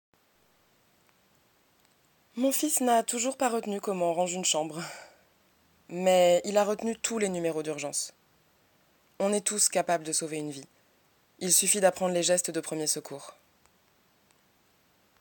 Voix off
Voix off Docu, Pub, Voice over, Audio guide, Audiodescription, livre audio, e-learning,...